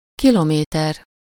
Ääntäminen
Vaihtoehtoiset kirjoitusmuodot (amerikanenglanti) kilometer chilometer (vanhentunut) chilometre Synonyymit klick km (amerikanenglanti) Km kilo Ääntäminen US UK : IPA : /ˈkɪləˌmiːtə/ IPA : /kɪˈlɒmɪˌtə/ US : IPA : /kəˈlɑmətɚ/ IPA : [kʰəˈɫɑmɪ̈ɾɚ] IPA : /ˈkɪləˌmitɚ/